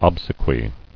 [ob·se·quy]